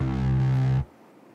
Bass (Power).wav